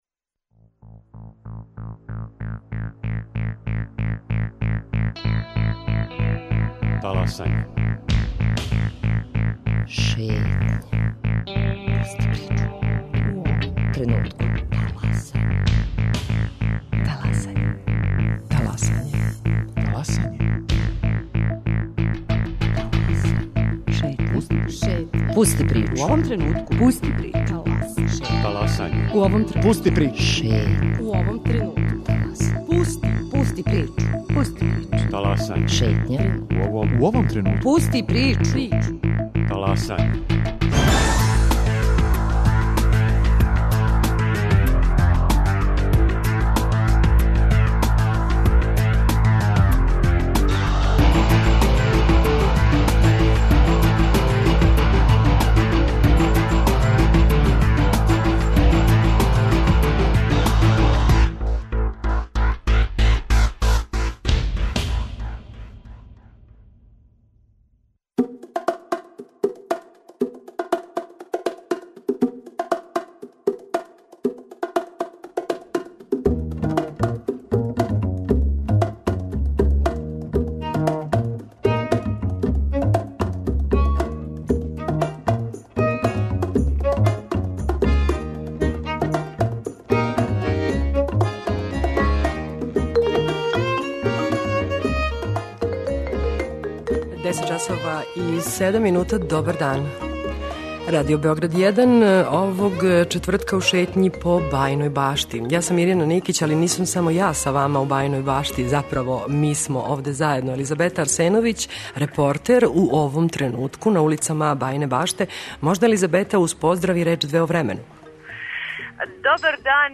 Овога четвртка шетамо Бајином Баштом. Емисију емитујемо уживо из овог града, упознаћемо вас са његовим житељима, предивном природом која га окружује, културним добрима...